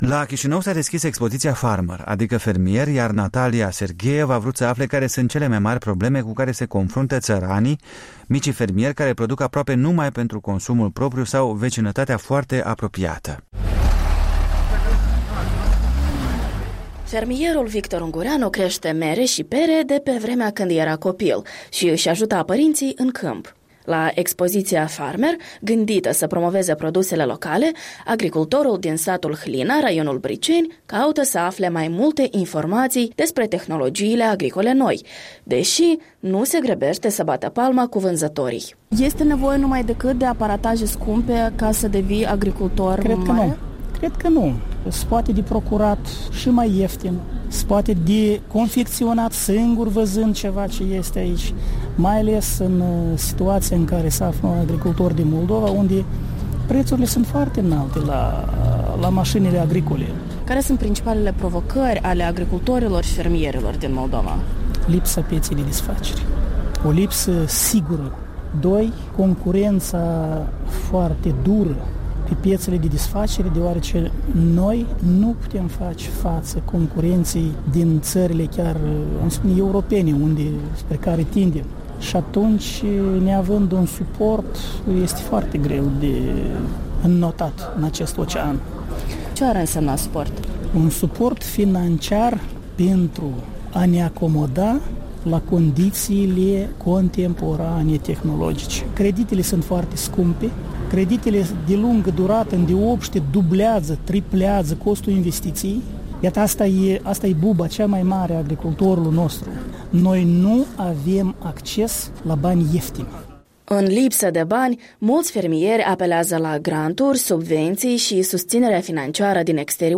Agricultori moldoveni își spun păsurile la expoziția „Farmer”